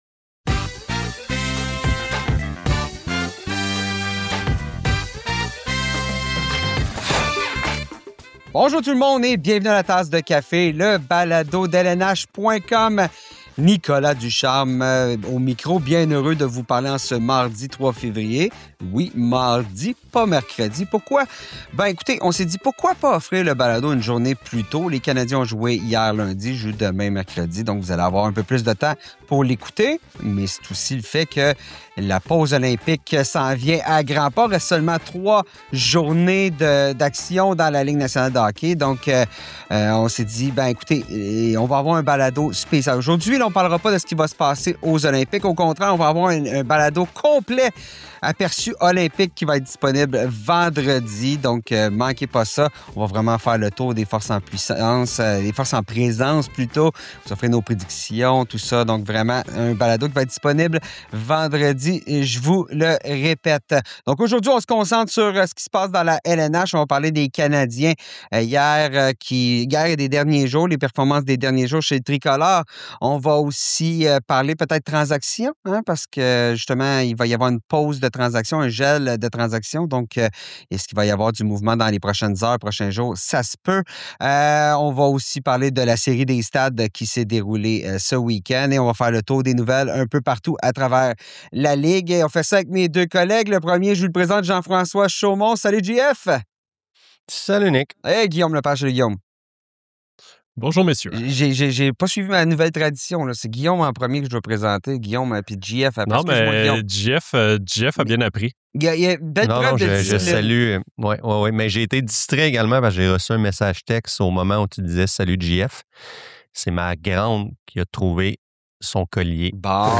Nos journalistes discutent aussi des derniers matchs des Canadiens et de la stratégie que l’équipe doit adopter d’ici à la pause olympique, dont l’utilisation des gardiens Jakub Dobes et Samuel Montembeault ainsi que du marché des transactions.